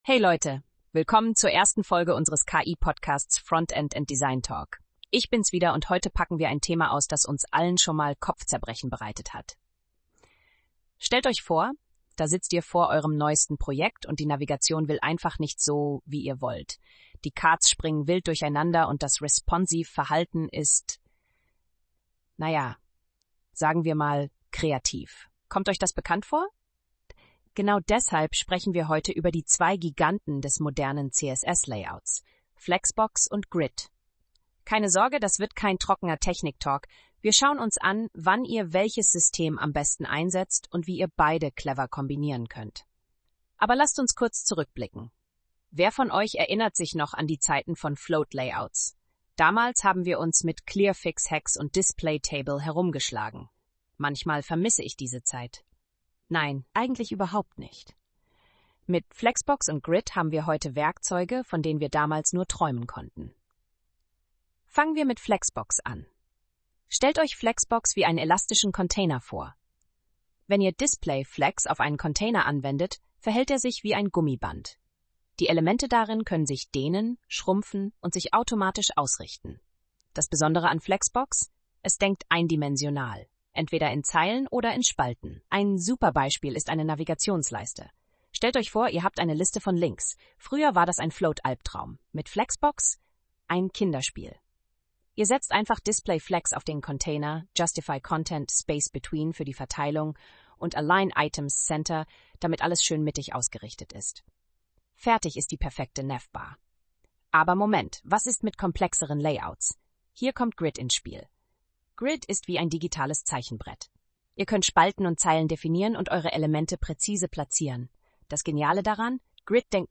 Ein erfahrener Webdesigner erklärt den strategischen Einsatz von Flexbox und Grid für optimale Layouts. Die Episode zeigt, wie diese Layout-Systeme für SEO-freundliche und responsive Websites eingesetzt werden können und welche praktischen Vorteile sie im modernen Webdesign bieten.